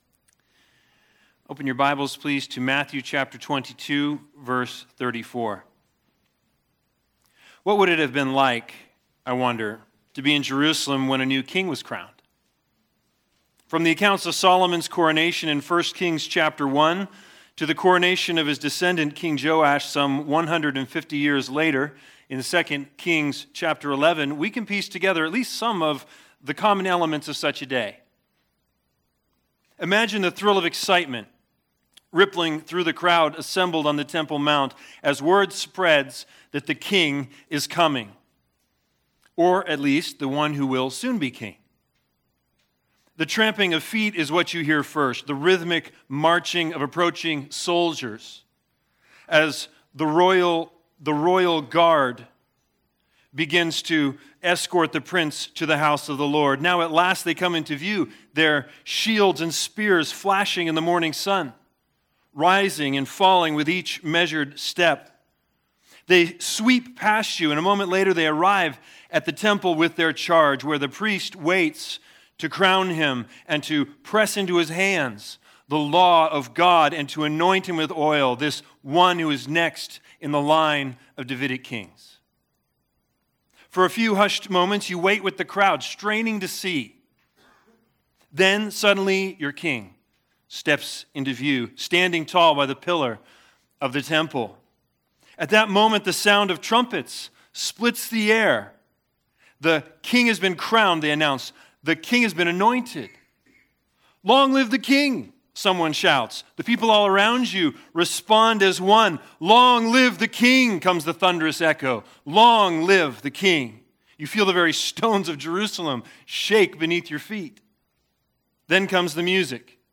Matthew 22:34-40 Service Type: Sunday Sermons The Big Idea